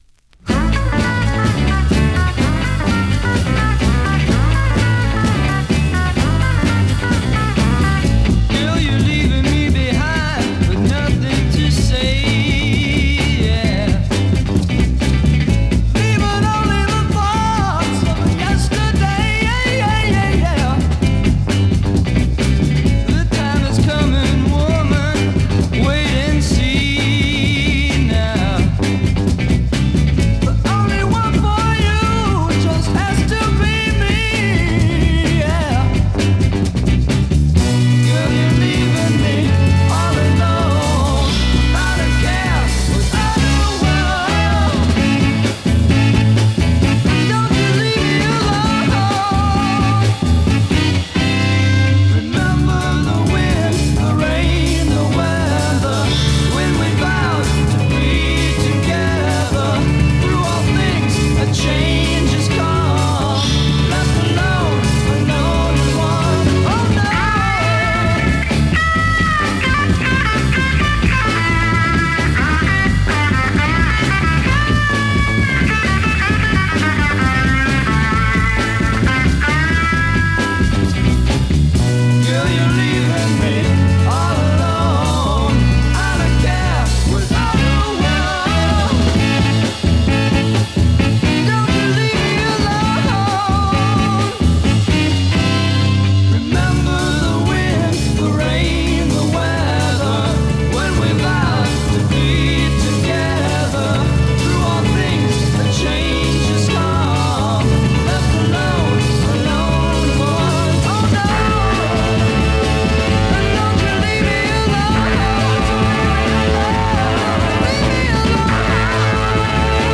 Killer psych garage.
試聴 (実際の出品物からの録音です)